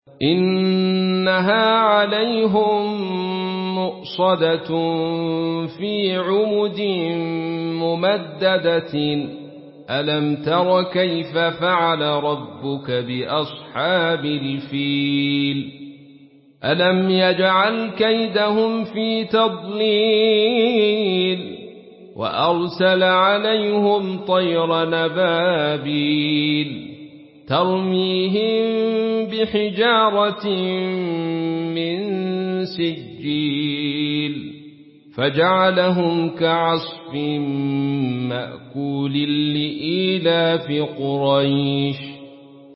Une récitation touchante et belle des versets coraniques par la narration Khalaf An Hamza.
Murattal